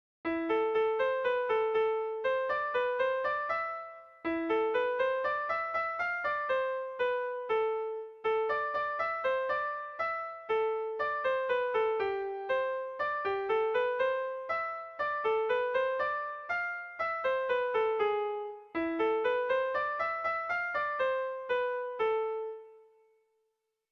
Sentimenduzkoa
ABDE..